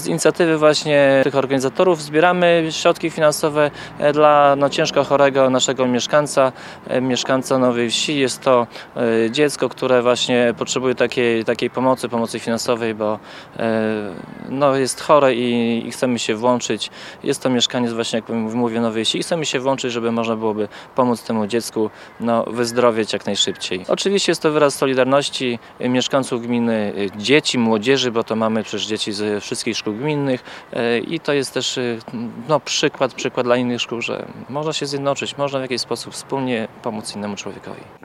Za solidarność dziękował zebranym Zbigniew Mackiewicz, wójt gminy Suwałki.